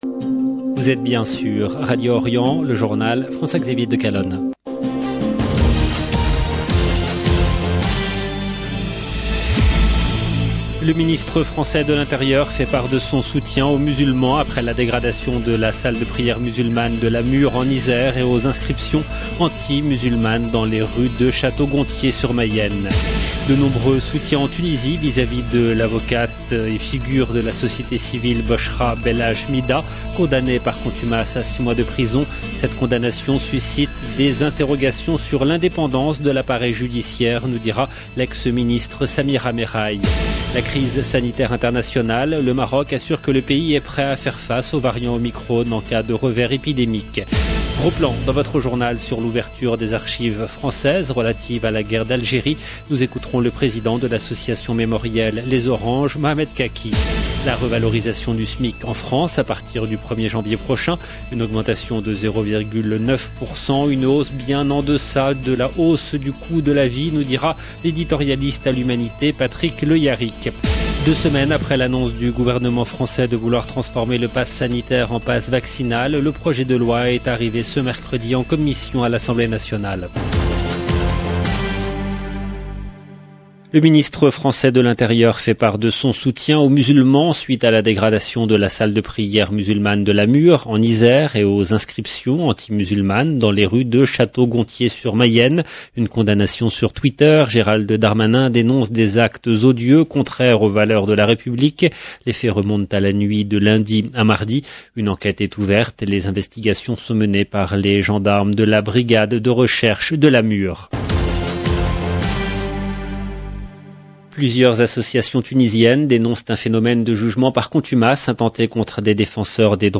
LE JOURNAL DU SOIR EN LANGUE FRANCAISE DU 29/12/21